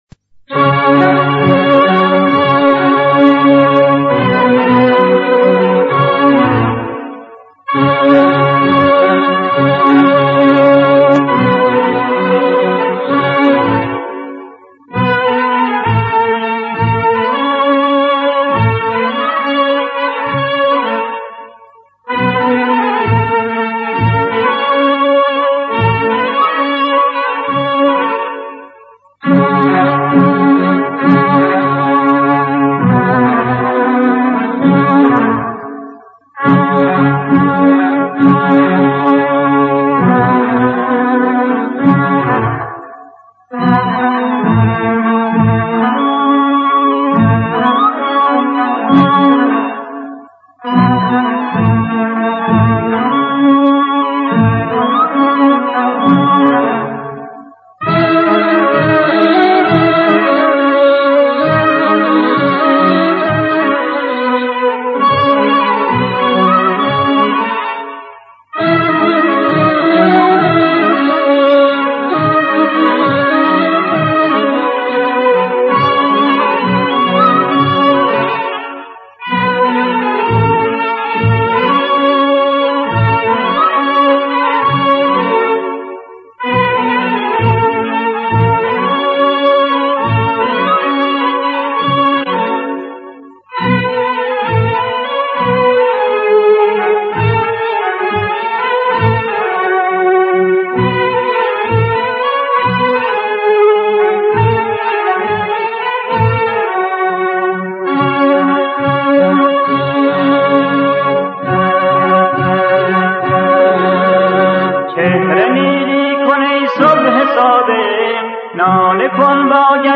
مداحی حاج صادق آهنگران ویژه ارتحال امام خمینی(ره)